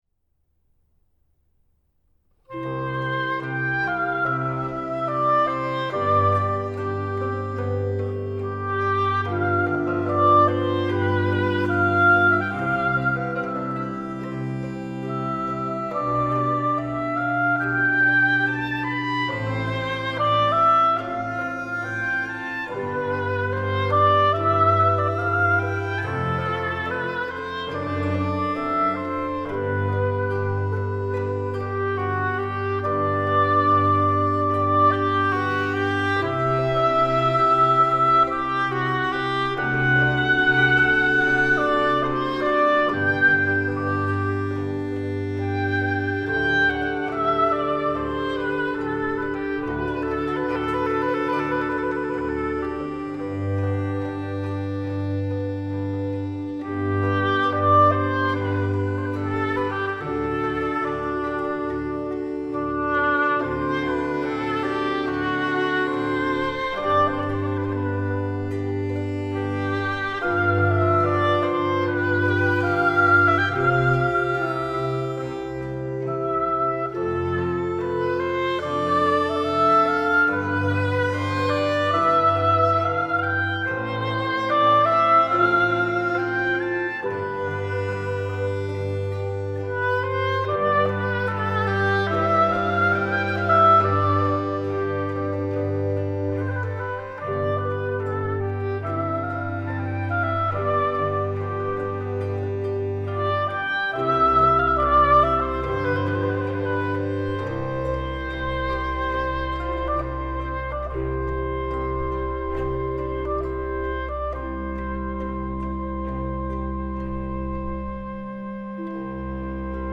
优美的双簧管音乐